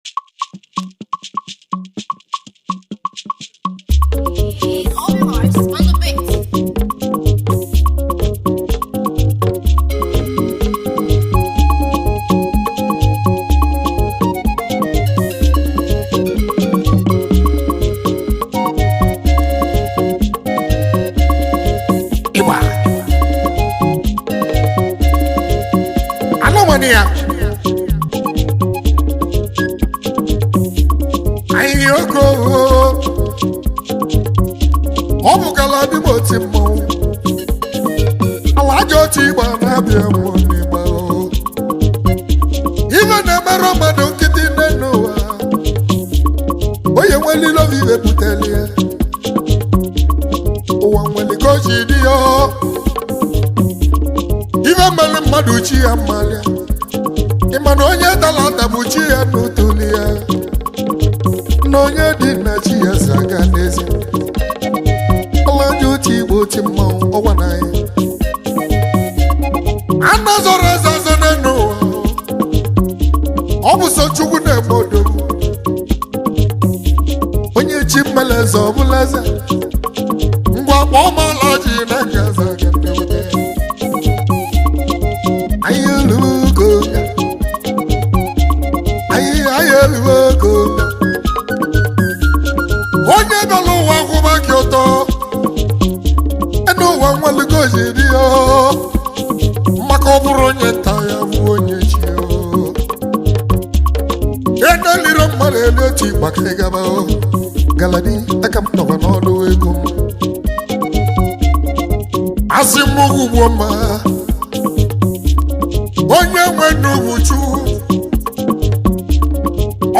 highlife track
highlife tune
Bongo Highlife Ogene